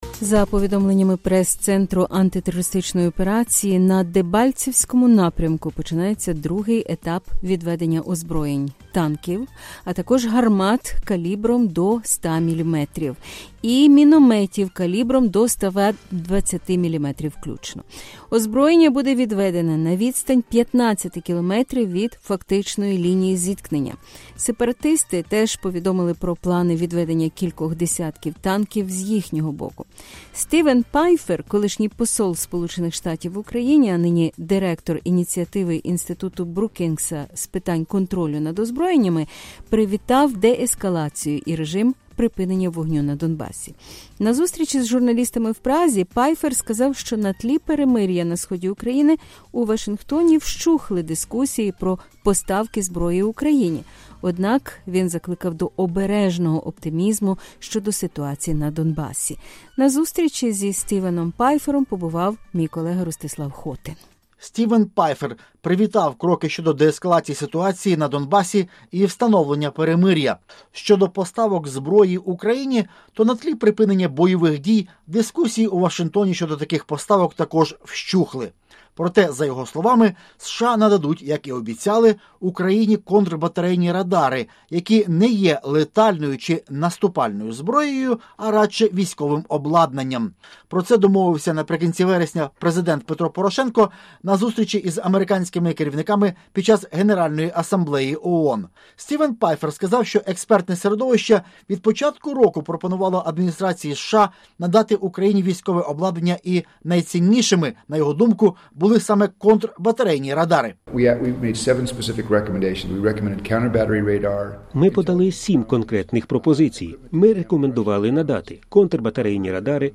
На зустрічі з журналістами в Празі Пайфер сказав, що на тлі перемир’я на сході України у Вашингтоні вщухли дискусії про поставки зброї Україні, однак закликав до обережного оптимізму щодо ситуації на Донбасі.
Стівен Пайфер в Американському центрі у Празі привітав кроки щодо деескалації ситуації на Донбасі й встановлення перемир’я, яке вже реально тримається понад півтора місяця.